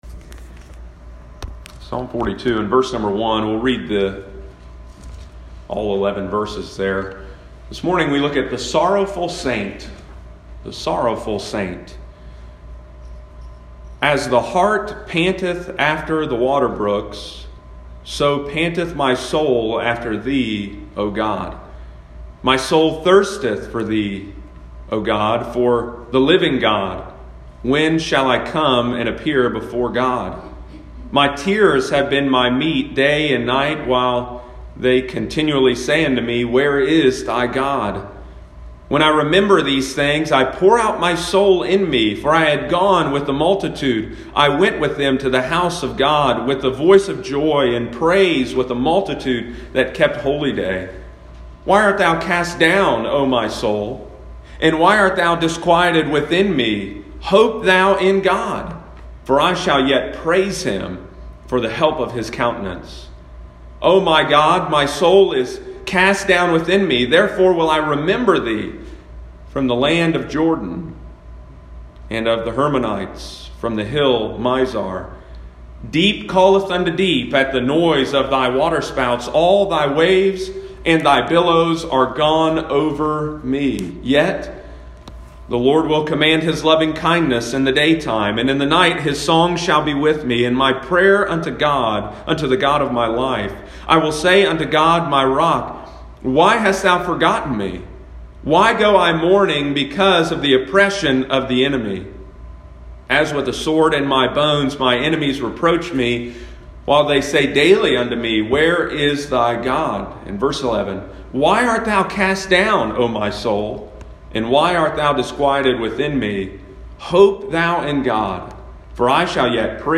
Sunday morning, July 12, 2020.